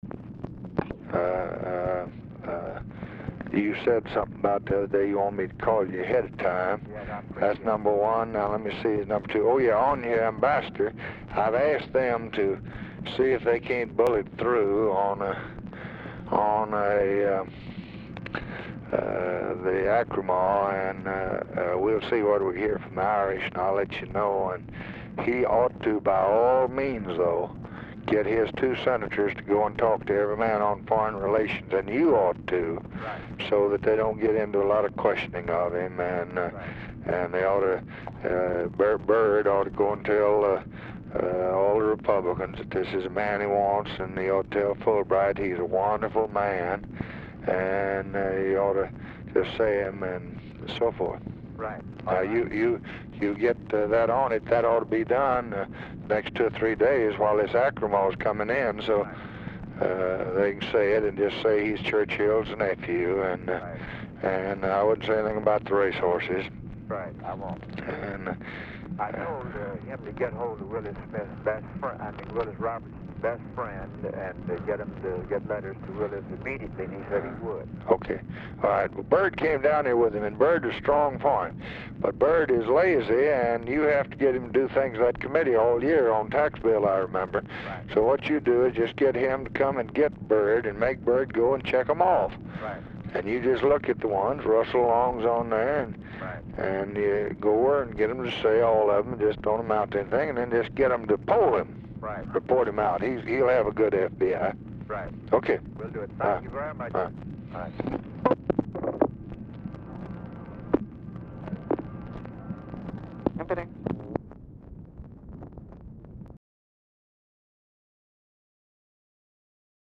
Telephone conversation # 6725, sound recording, LBJ and GEORGE SMATHERS, 1/12/1965, 5:25PM | Discover LBJ
RECORDING STARTS AFTER CONVERSATION HAS BEGUN
Format Dictation belt
Location Of Speaker 1 Oval Office or unknown location